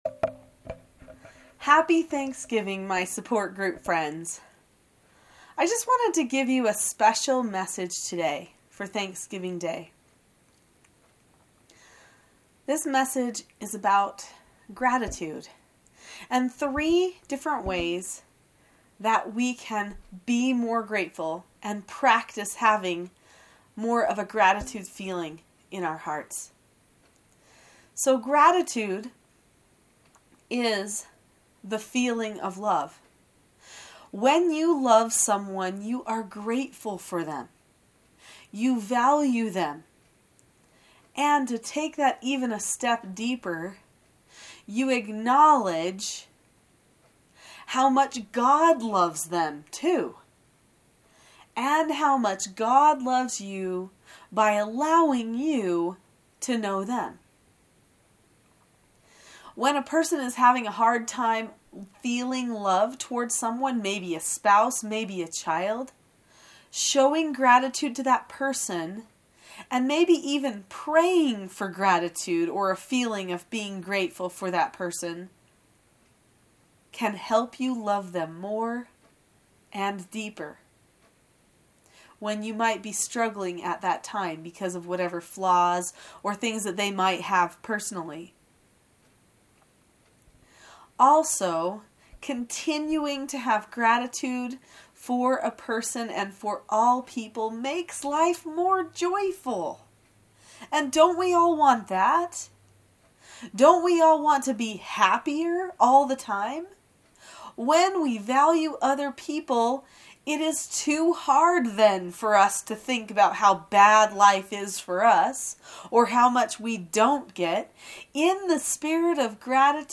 Listen to this short audio class about three ways you can practice having more gratitude in your life.